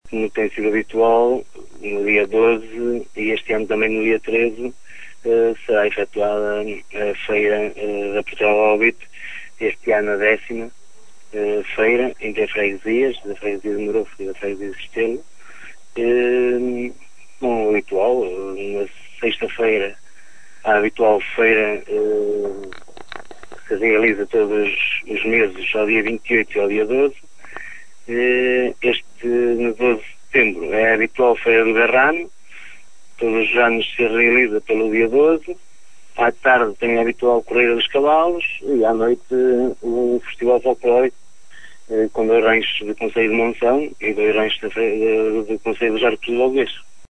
Márcio Alves, presidente da junta de Merufe, adianta mais pormenores.